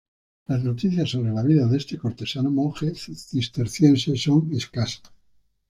Read more Frequency C1 Hyphenated as mon‧je Pronounced as (IPA) /ˈmonxe/ Etymology Inherited from Old Spanish monje In summary From Old Spanish monje, from Old Occitan monge, from Late Latin monicus, variant of monachus.